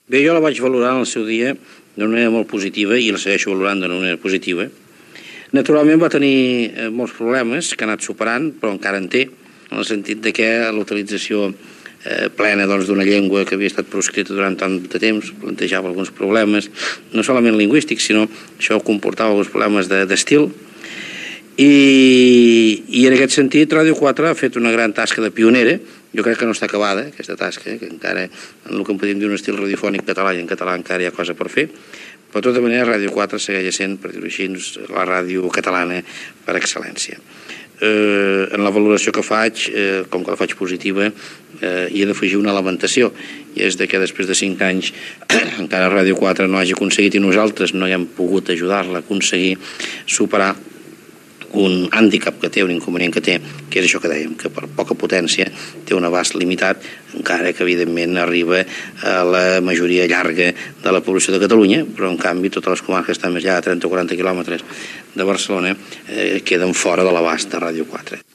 Valoració del president de la Generalitat Jordi Pujol sobre Ràdio 4 en el seu cinquè aniversari
Extret del programa "Memòries de la ràdio" de Ràdio 4 emès el 28 de juny del 2011.